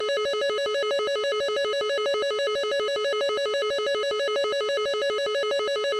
F18 Rwr Sound Soundboard: Play Instant Sound Effect Button